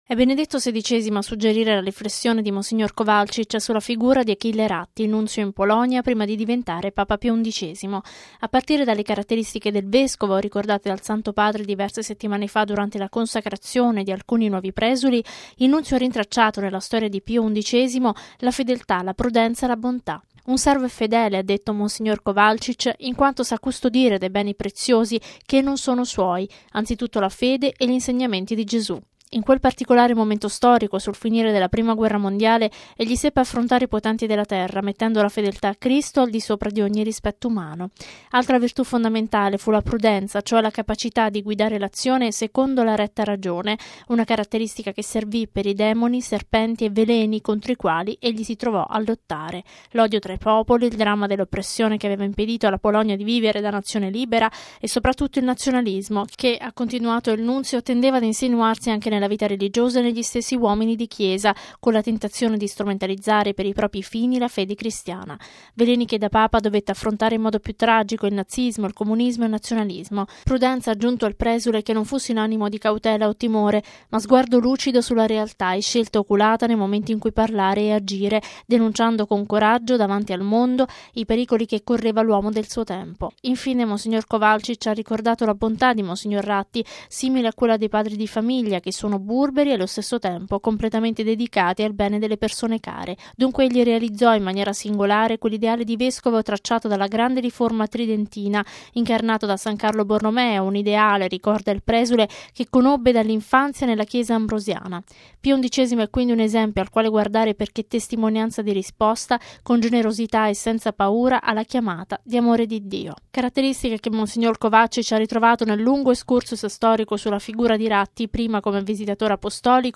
◊   “Servo fedele, prudente e buono”: così mons. Jozef Kowalczyk, nunzio apostolico in Polonia, ha tratteggiato la figura di Papa Pio XI nell’omelia per la messa di stamani celebrata nella Basilica parrocchiale di Desio, in provincia di Monza e Brianza.